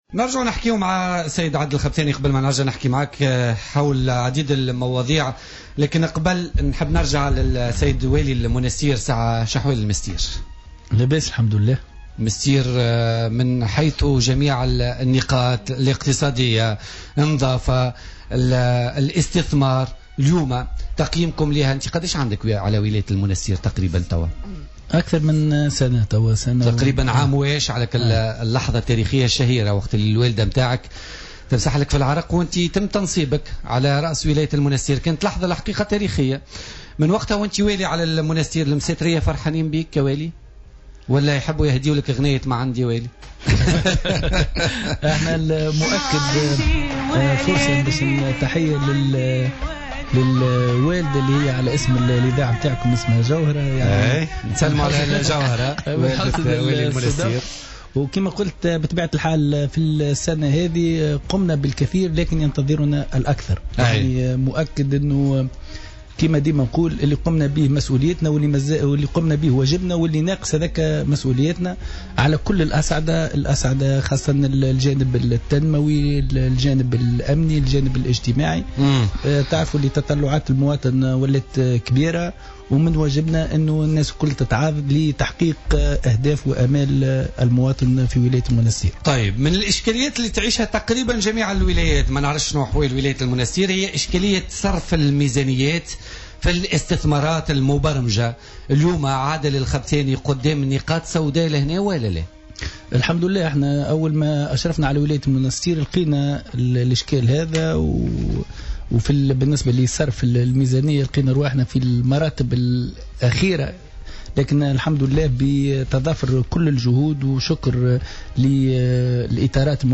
Le gouverneur de Monastir, Adel Khabthani, était l'invité de Politica du mercredi 16 novembre 2016.